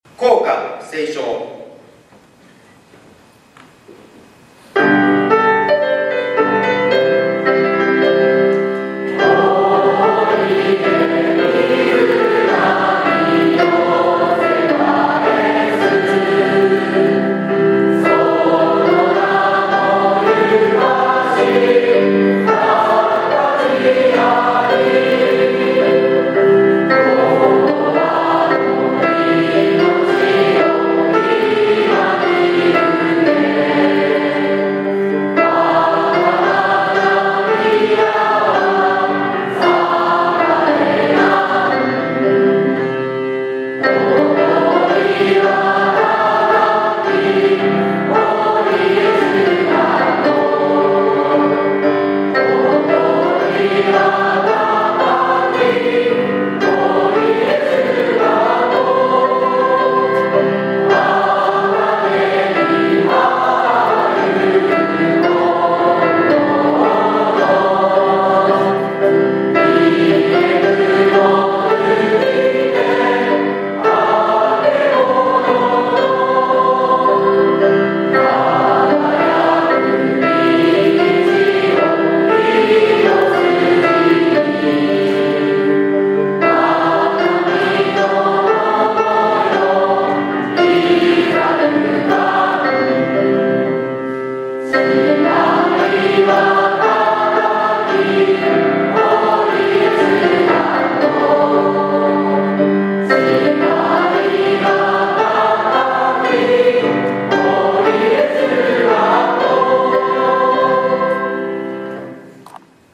第55回卒業式（3月12日）
本日、第55回の卒業証書授与式を挙行いたしました。